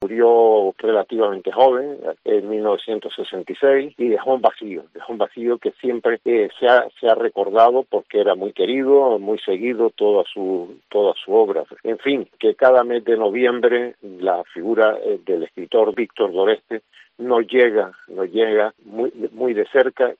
Juan José Laforet, cronista oficial de Las Palmas de Gran Canaria